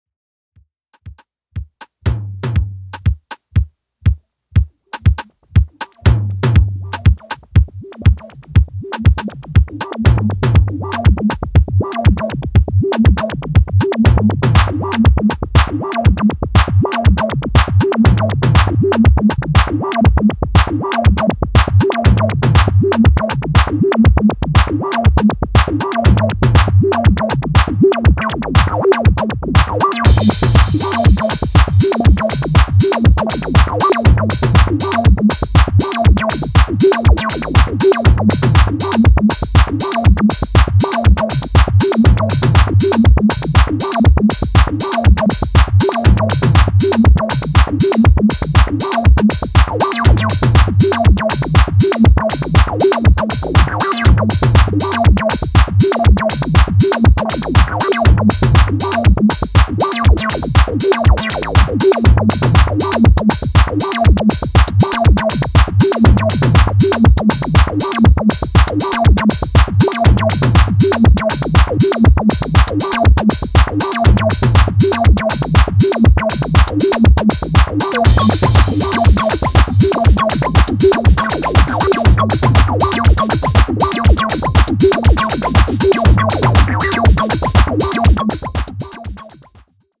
> HOUSE・TECHNO
ジャンル(スタイル) HOUSE